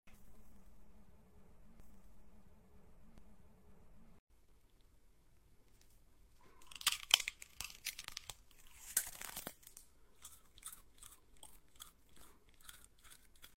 Tung tung tung sahur ASMR. sound effects free download